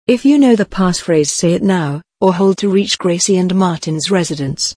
buzzer_welcome_boosted.protected.mp3